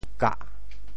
“合”字用潮州话怎么说？
kah4.mp3